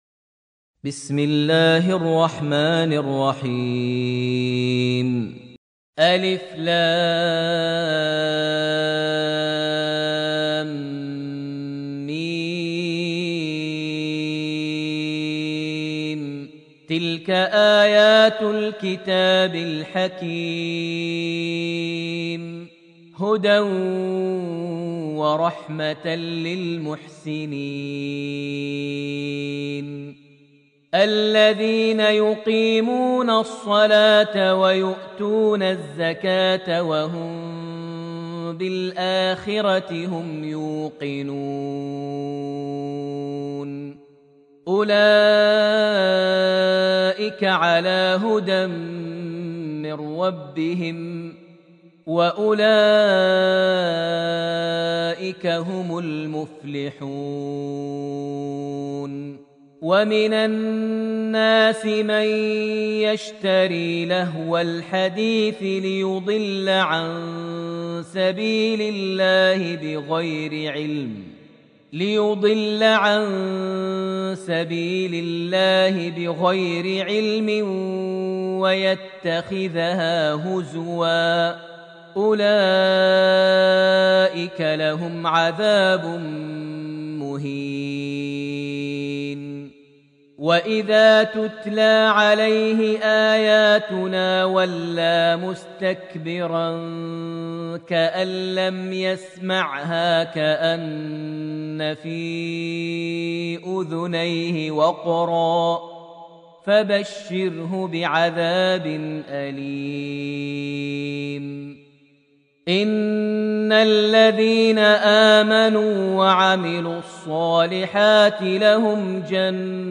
Surat Luqman > Almushaf > Mushaf - Maher Almuaiqly Recitations